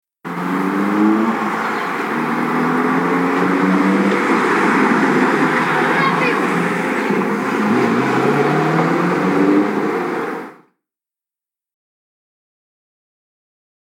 جلوه های صوتی
دانلود صدای ماشین 18 از ساعد نیوز با لینک مستقیم و کیفیت بالا